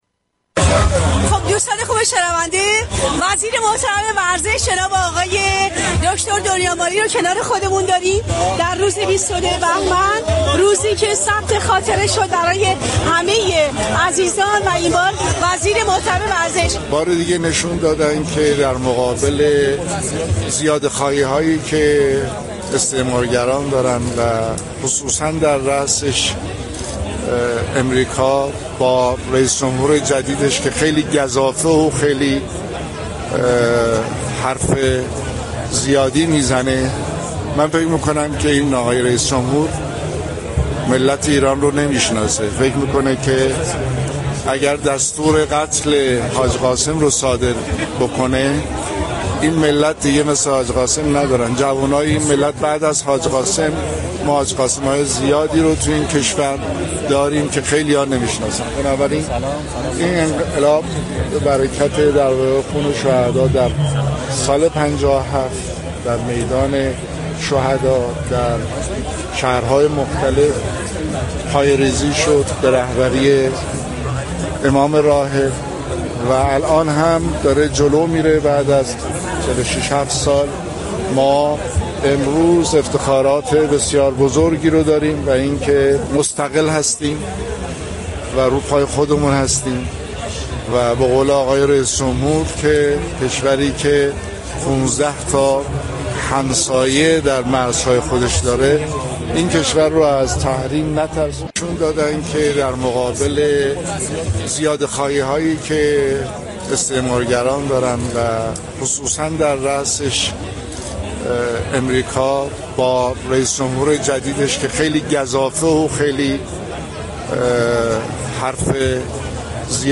به گزارش پایگاه اطلاع رسانی رادیو تهران، احمد دنیامالی وزیر ورزش و جوانان در گفت و گو با «اینجا تهران است» ویژه برنامه چهل و ششمین سالروز پیروزی انقلاب اسلامی اظهار داشت: رئیس جمهور آمریكا مردم ایران را نمی‌شناسد و فكر می‌كند اگر دستور قتل حاج قاسم سلیمانی را صادر كند این ملت دیگر همانند حاج قاسم سلیمانی را ندارد.